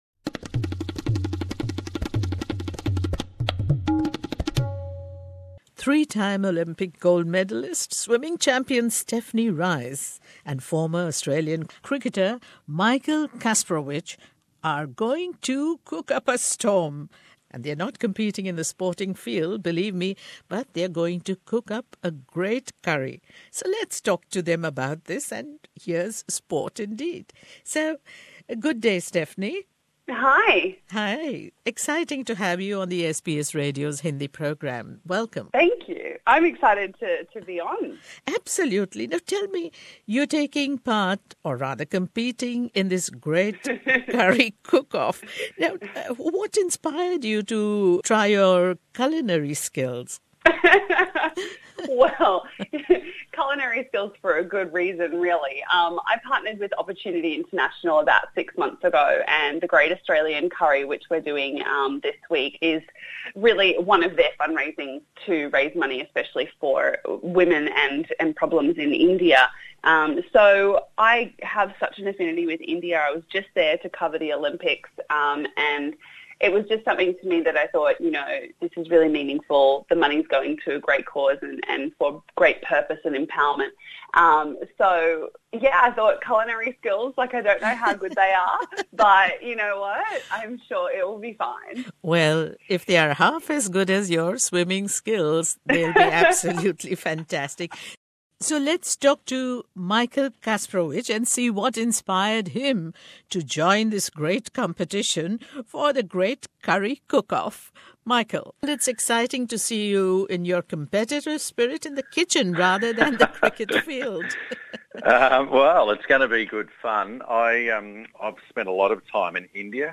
इस एक्सक्लूसिव भेंटवार्ता